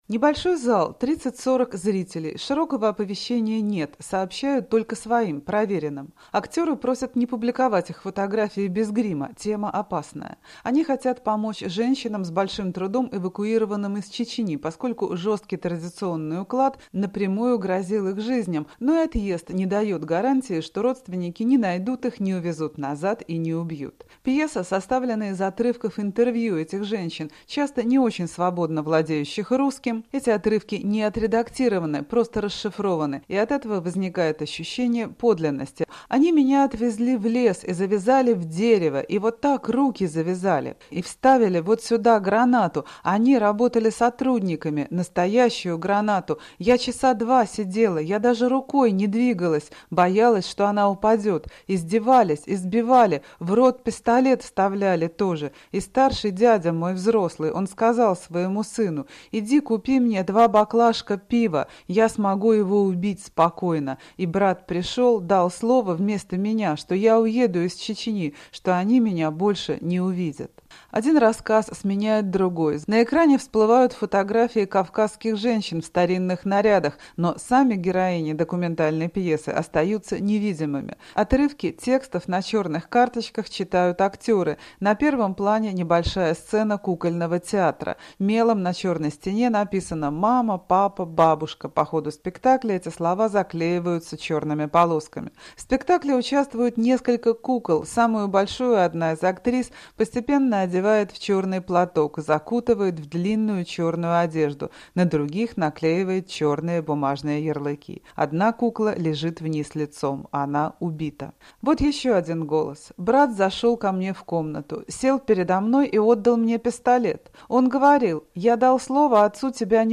Говорят участники и зрители спектакля "Голоса"